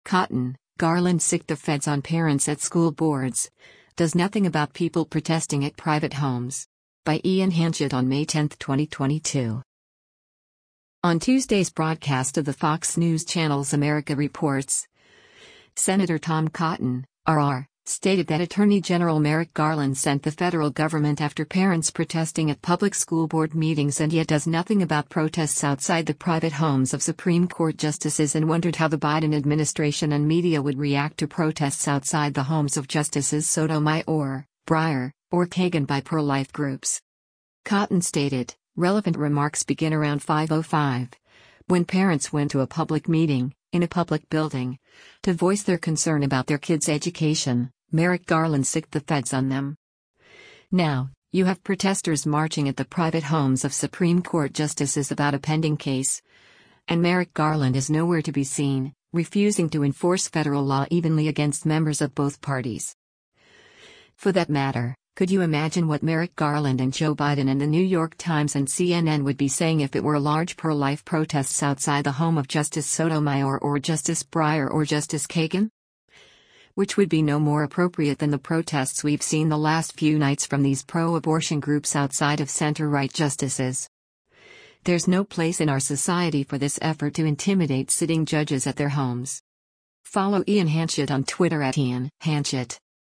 On Tuesday’s broadcast of the Fox News Channel’s “America Reports,” Sen. Tom Cotton (R-AR) stated that Attorney General Merrick Garland sent the federal government after parents protesting at public school board meetings and yet does nothing about protests outside the private homes of Supreme Court justices and wondered how the Biden administration and media would react to protests outside the homes of Justices Sotomayor, Breyer, or Kagan by pro-life groups.